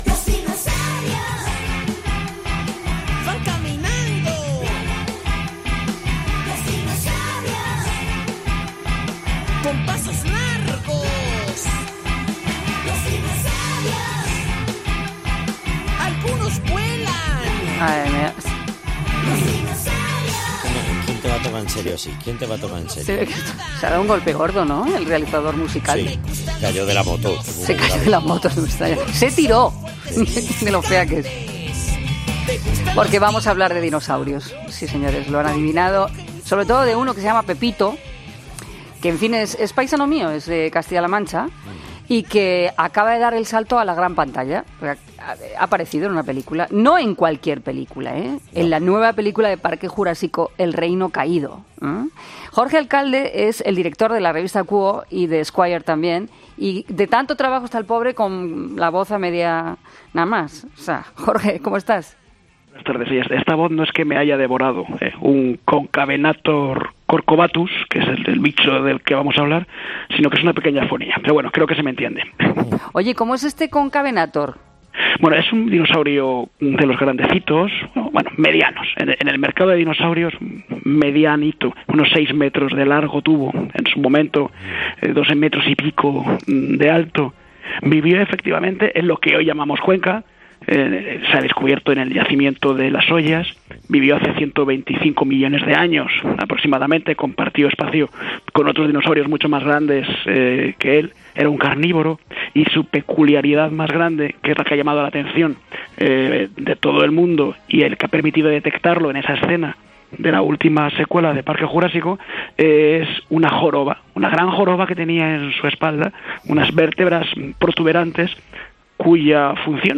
ha sido entrevistado este jueves en el programa debido al descubrimiento del fósil más famoso del yacimiento de Las Hoyas